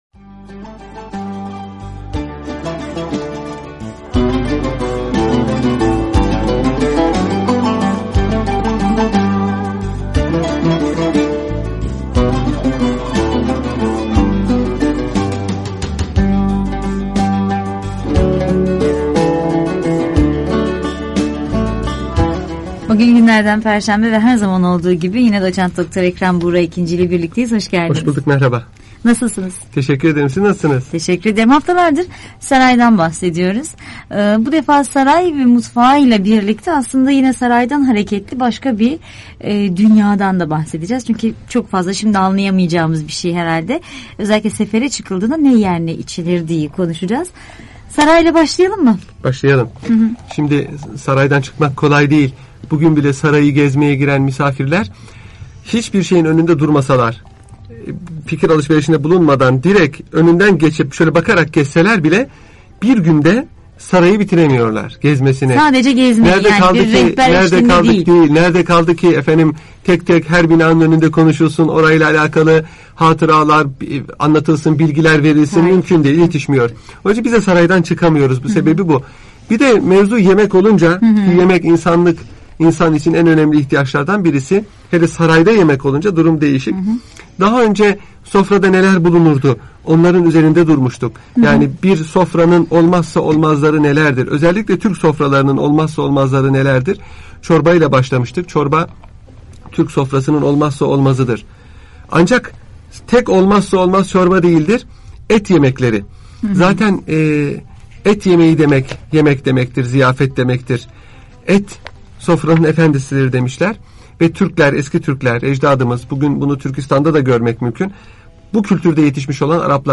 Radyo Programi - Osmanlı Saray Mutfağı